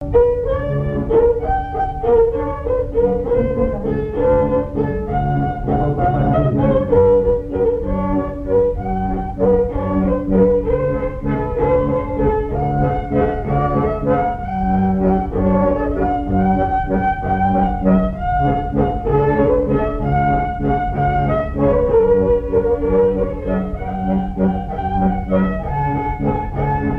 Mémoires et Patrimoines vivants - RaddO est une base de données d'archives iconographiques et sonores.
danse : valse
Airs à danser aux violons
Pièce musicale inédite